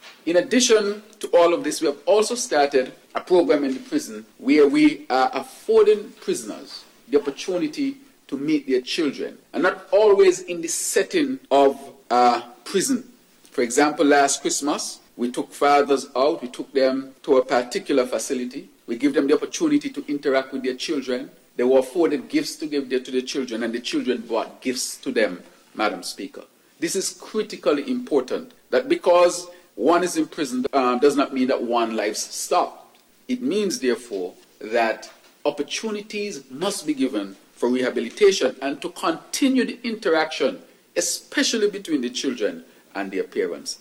2nd reading of the Prison (Amendment) Bill, 2025 heard Thursday in the National Assembly